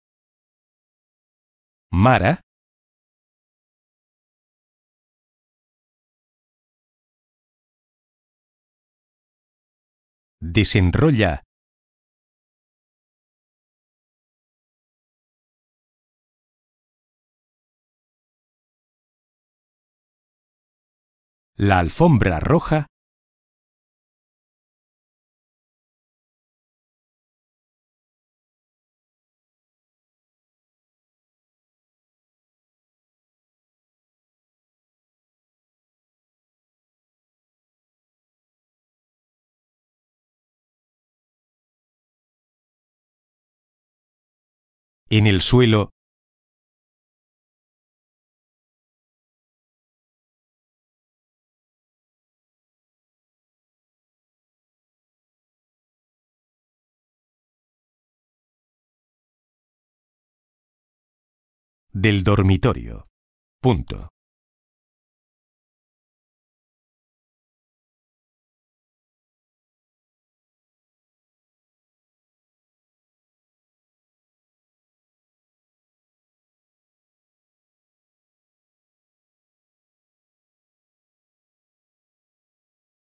Hoy vamos a hacer un dictadito, fijándonos bien en todas las cosas que hemos aprendido hasta ahora.
Dictado-2.ogg